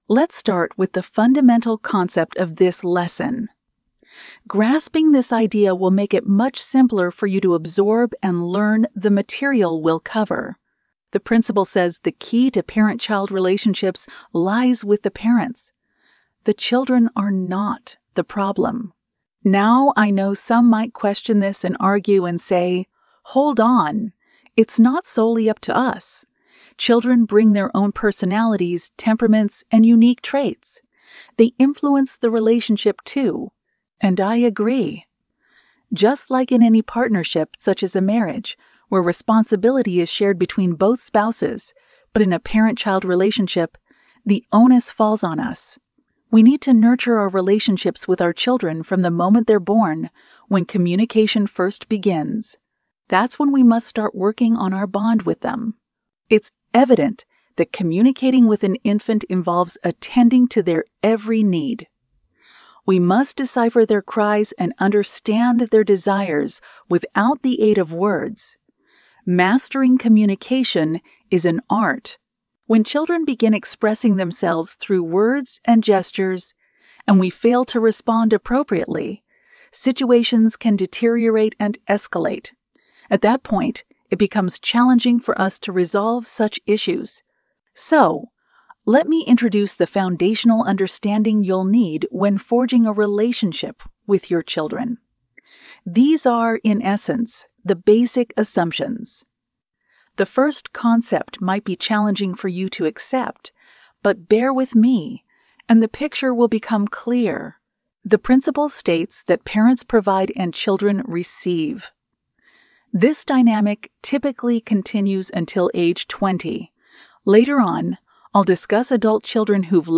Lecture 3: What Parents Should Know- part1